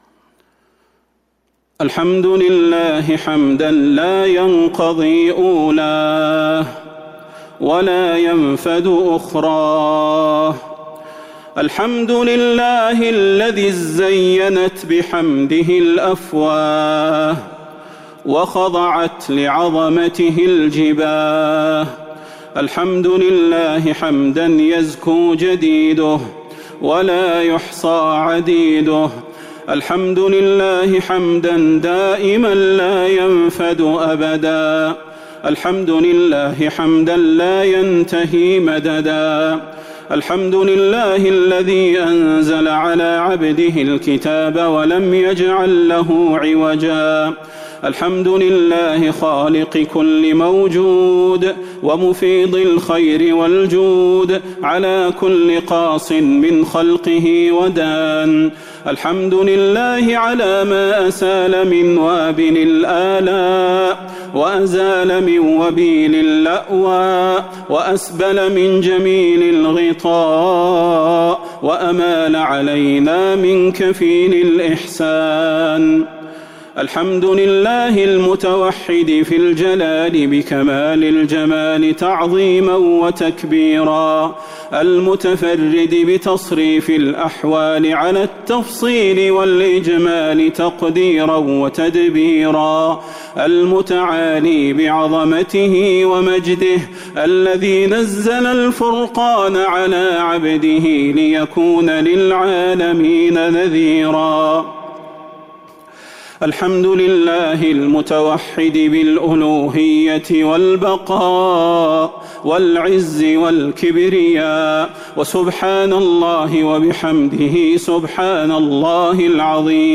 دعاء ختم القرآن ليلة 29 رمضان 1442هـ | Dua for the night of 29 Ramadan 1442H > تراويح الحرم النبوي عام 1442 🕌 > التراويح - تلاوات الحرمين